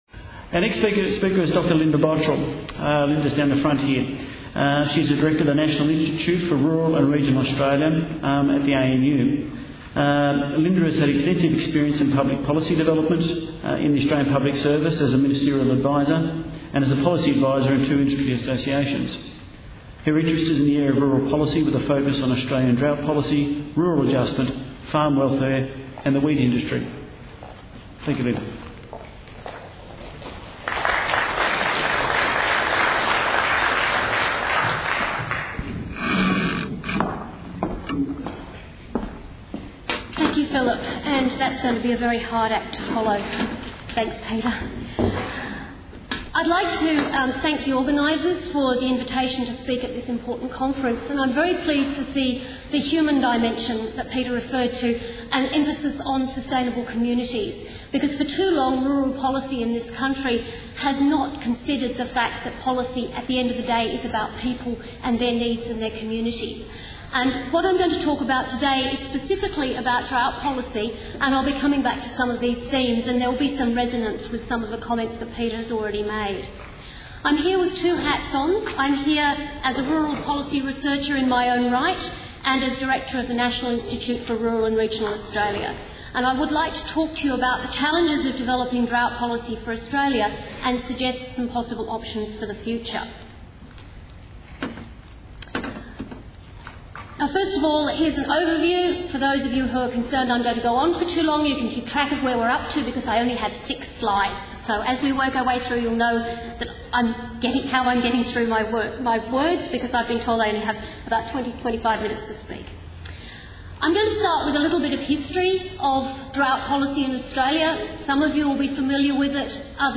Challenges of drought policy and options for the future- Sustaining Rural Communities Conference 2010 Presentation Audio 40 min | Inside Cotton